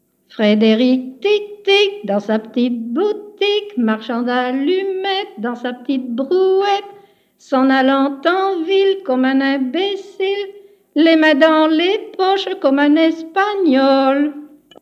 Type : chanson énumérative ou à récapitulation | Date : 15 septembre 1959